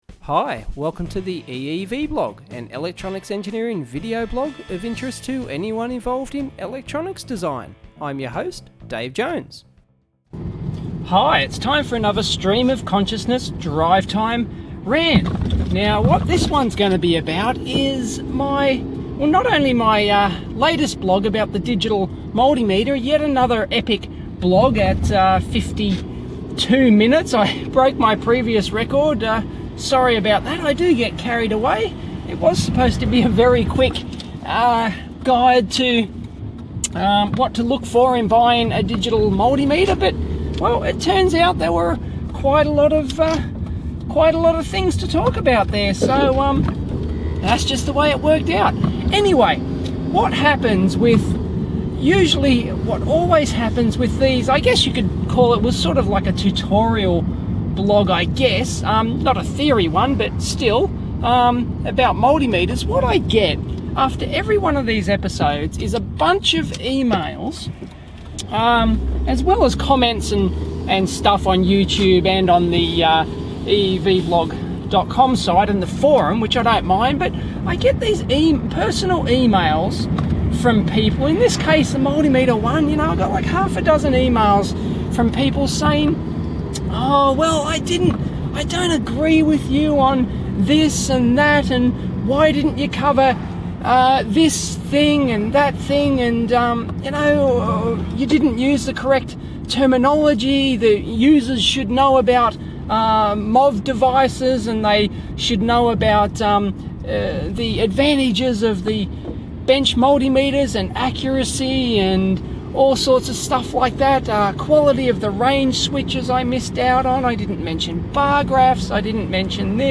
Another drive-time rant.